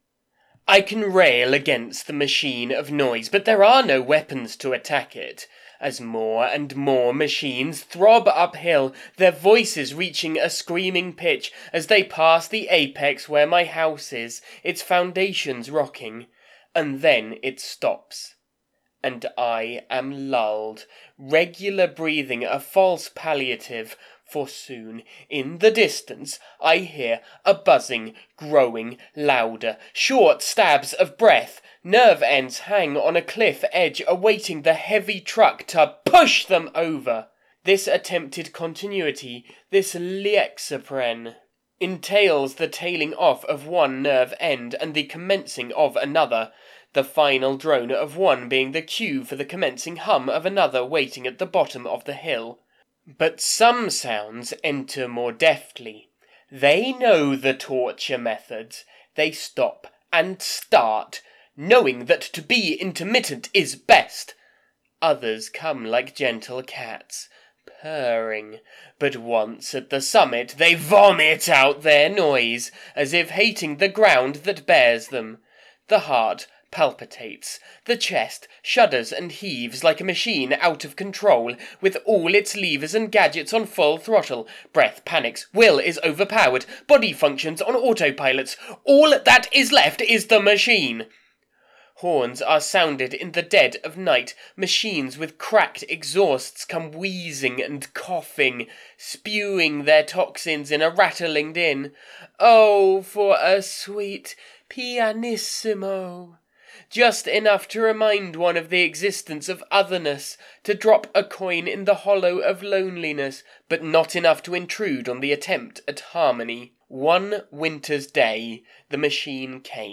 This is where all my favorite books are, if you just want an audiobook that is “the best i’ve narrated” regardless of genre then this is the place.